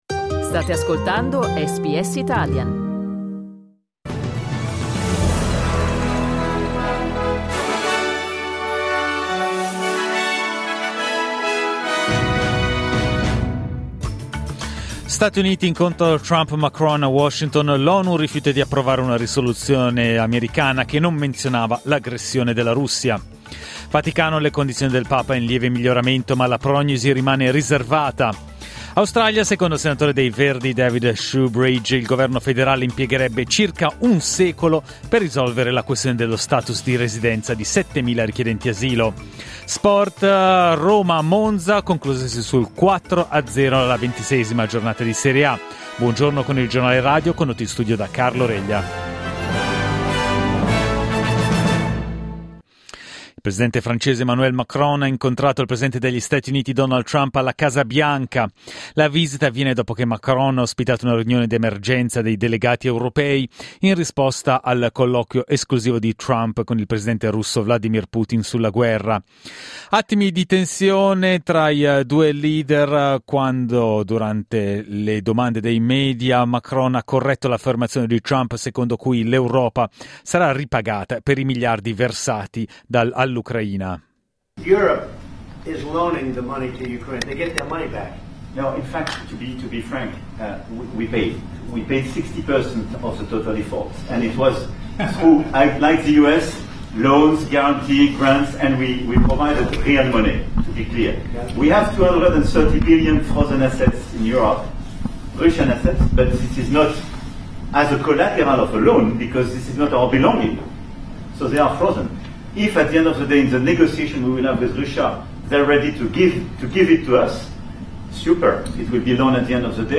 Giornale radio martedì 25 febbraio 2025
Il notiziario di SBS in italiano.